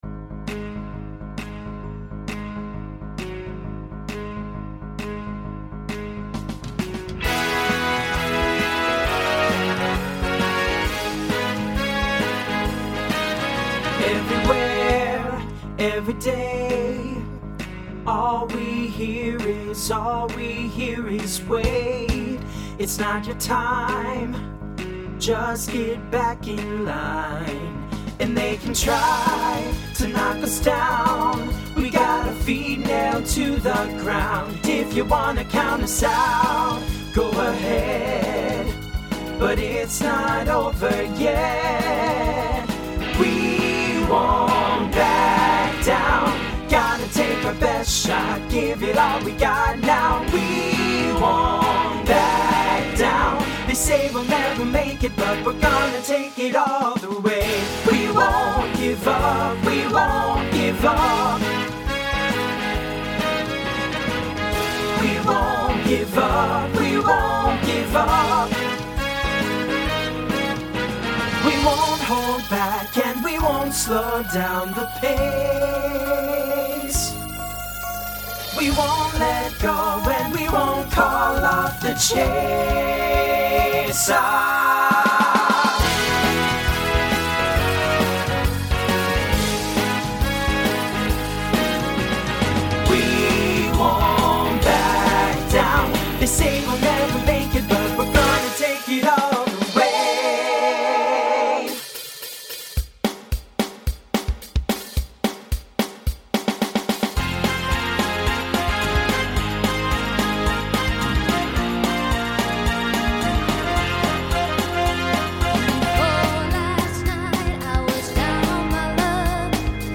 TTB/SSA
Voicing Mixed Instrumental combo Genre Pop/Dance , Rock